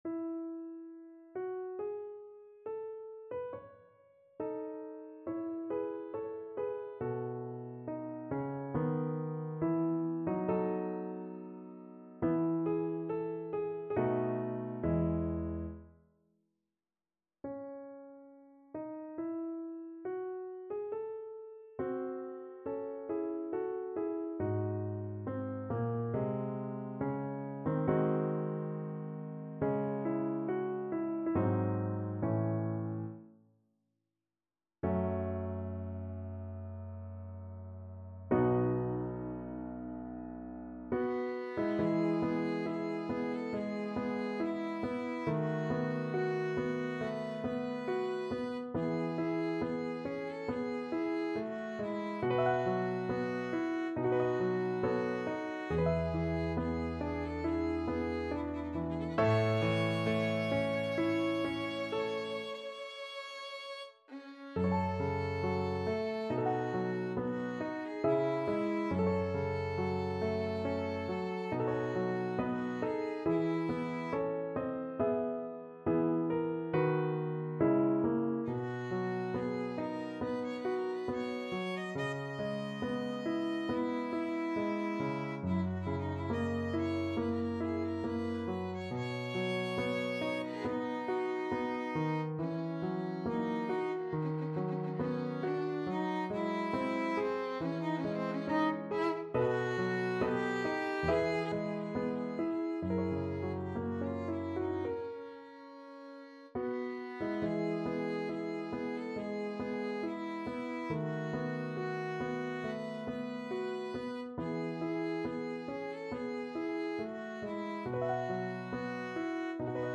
4/4 (View more 4/4 Music)
Larghetto (=80) =69
Bb4-Eb6
Classical (View more Classical Violin Music)